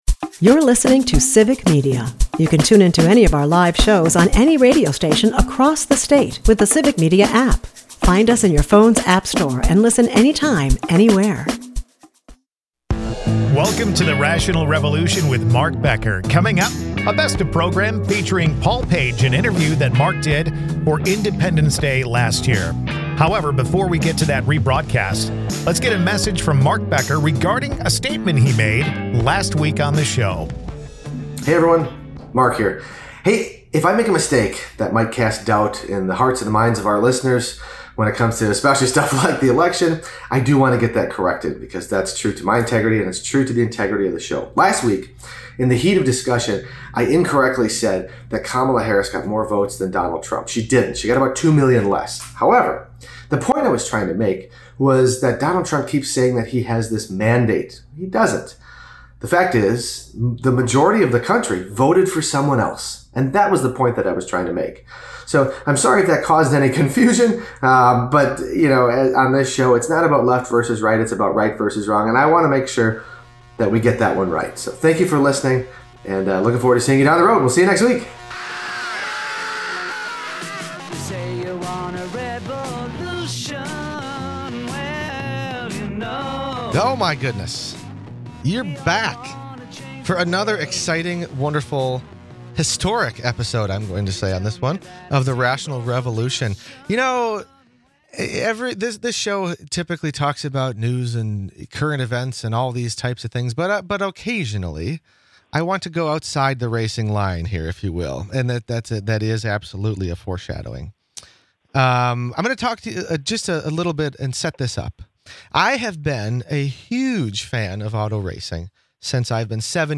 Guest: Paul Page